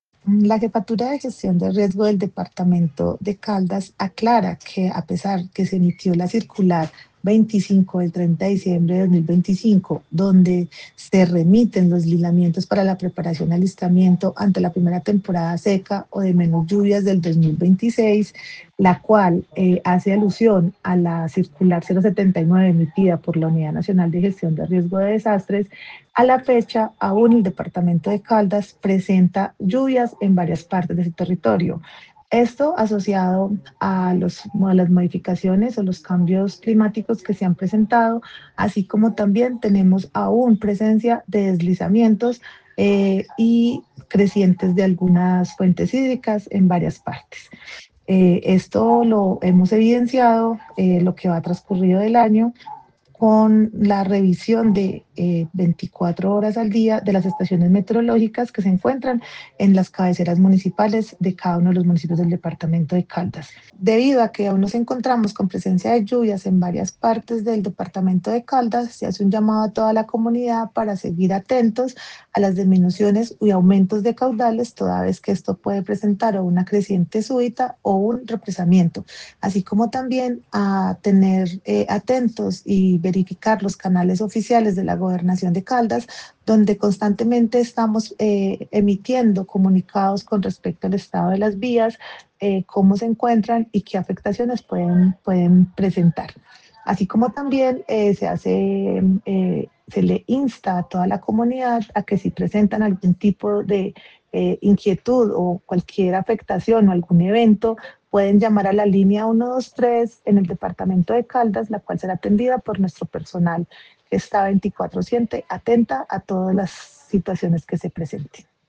Paula Marcela Villamil Rendón, jefe de Gestión del Riesgo de Caldas.